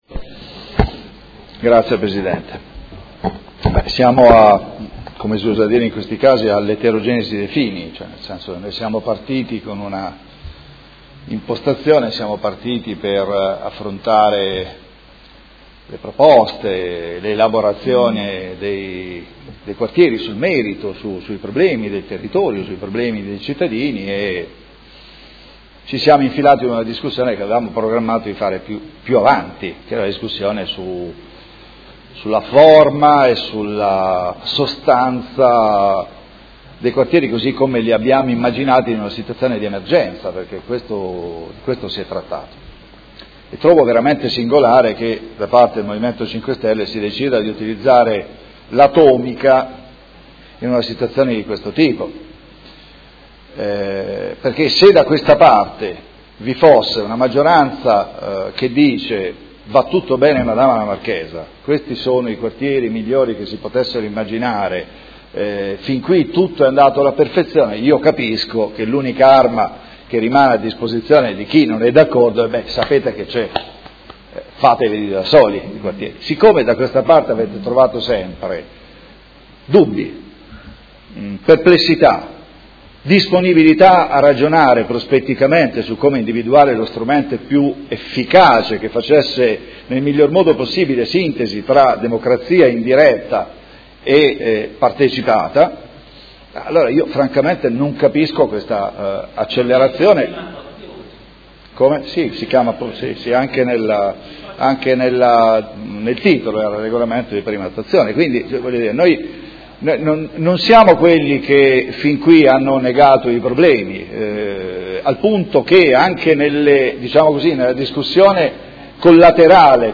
Seduta del 30/05/2016. Situazione dei Quartieri a Modena – dibattito